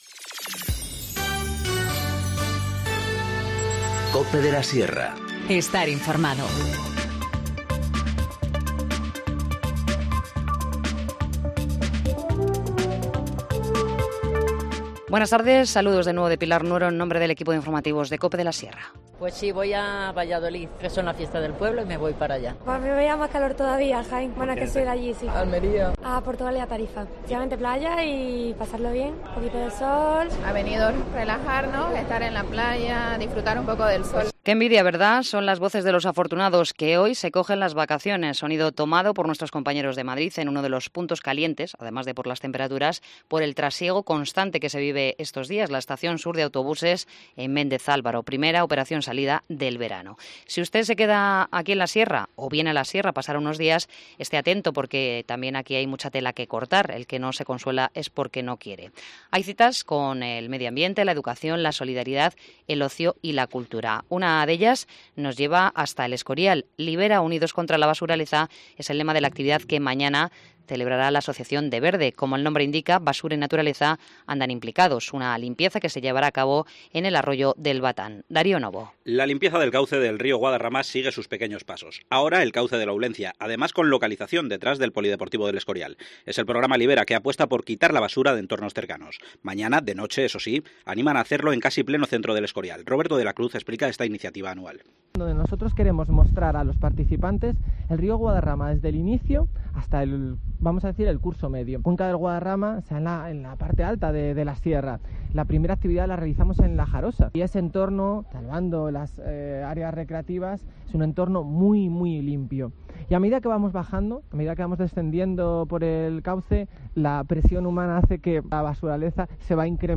Informativo Mediodía 28 junio 14:50h